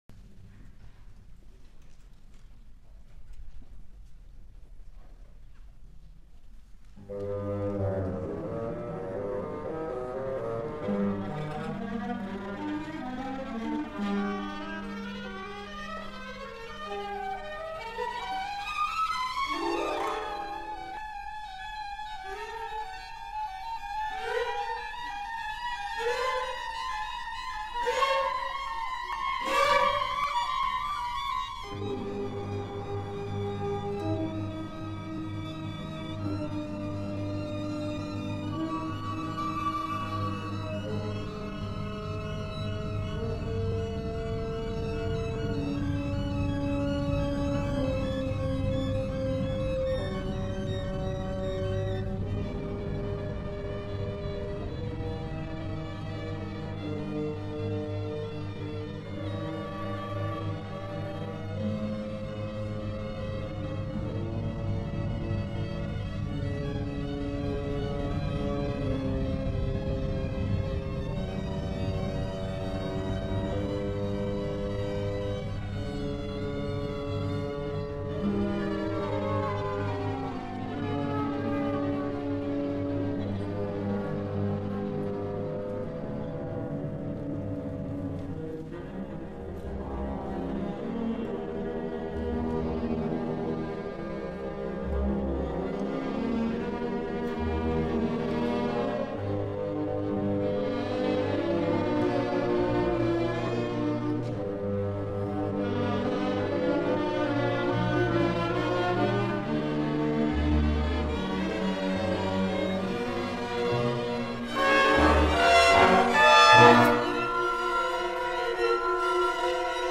Suite for Orchestra